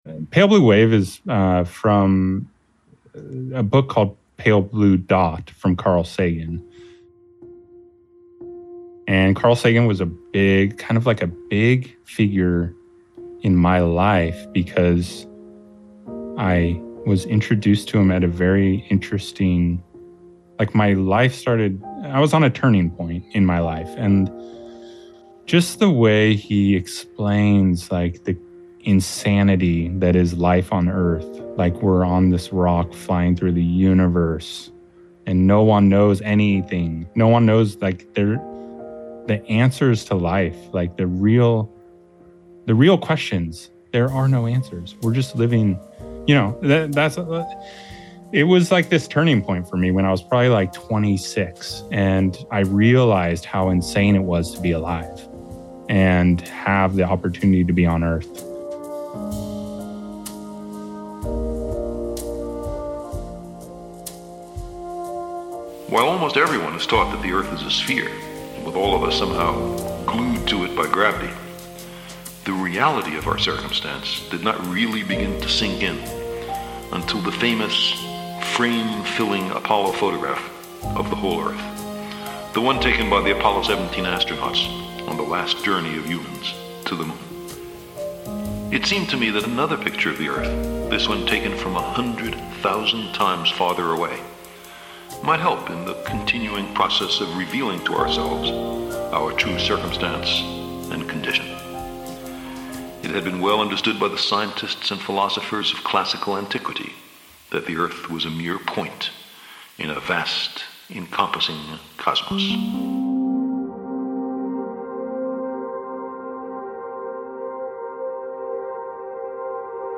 live synth jams